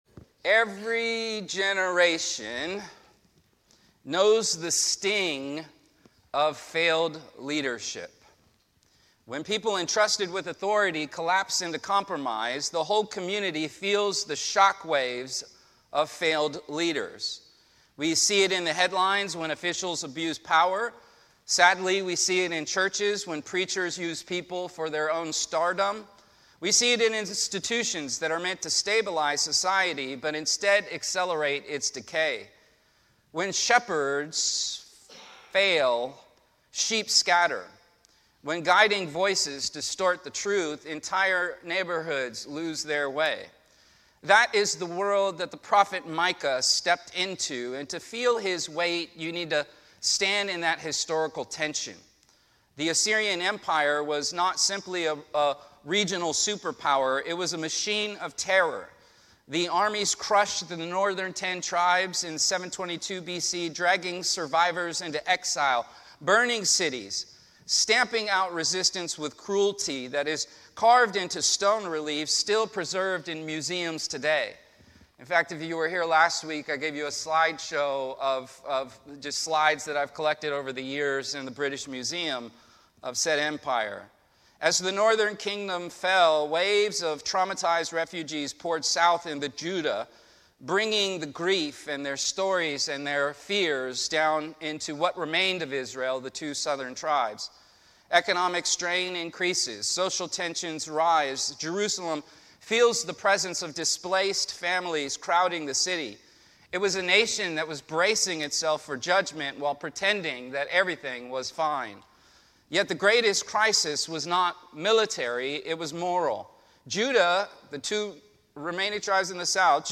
Sermons
Sermons from Del Rey Church: Playa Del Rey, CA